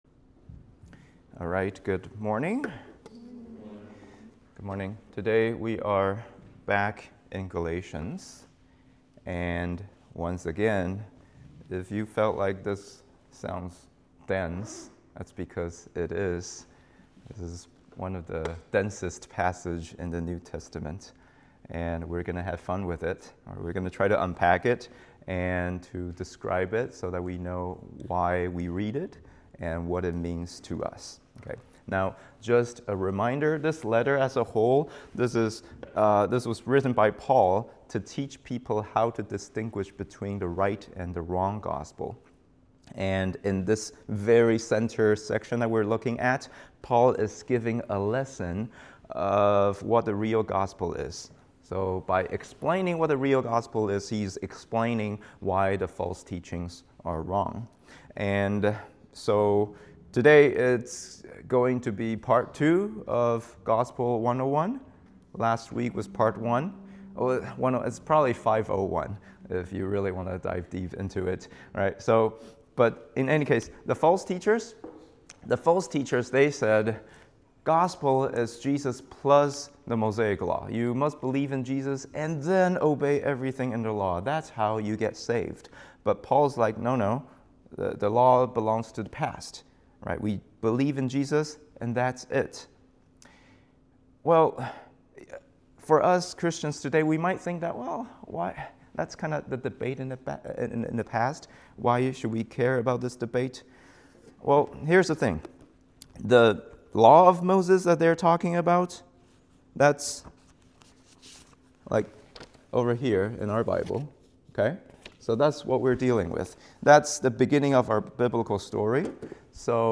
3.22-English-Sermon.m4a